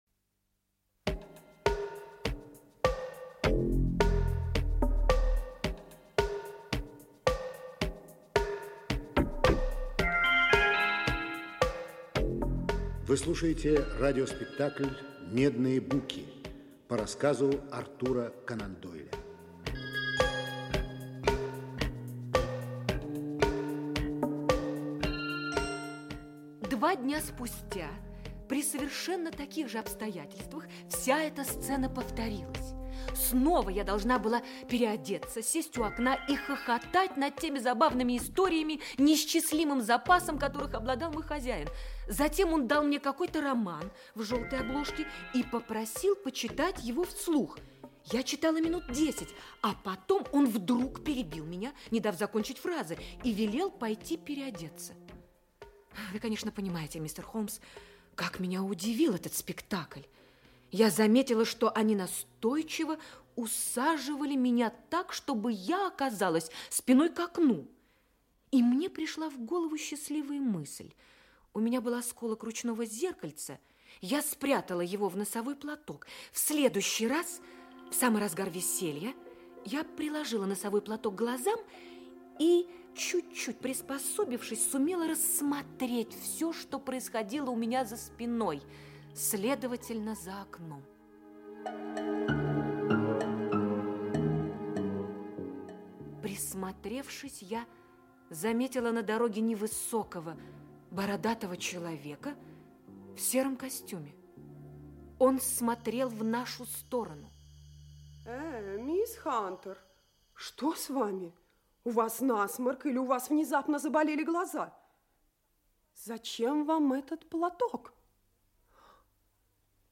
Аудиокнига Медные буки. Часть 2 | Библиотека аудиокниг
Часть 2 Автор Артур Конан Дойл Читает аудиокнигу Актерский коллектив.